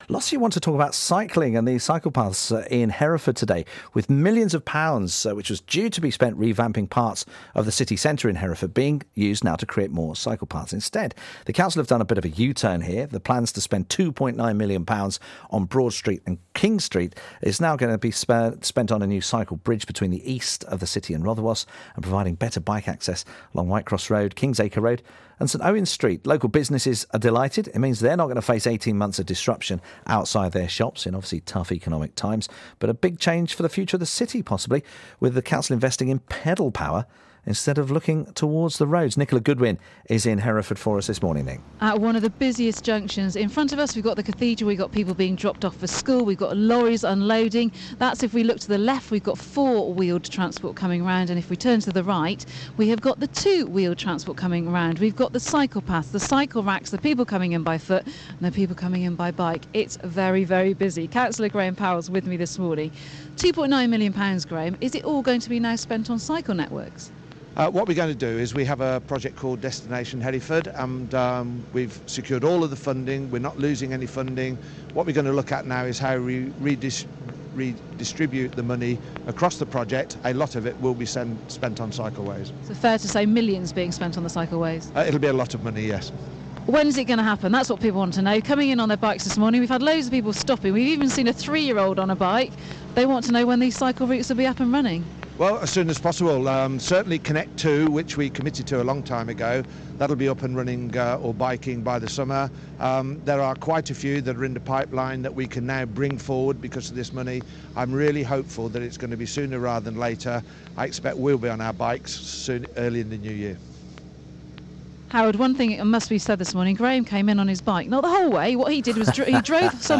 was live in Broad Street this morning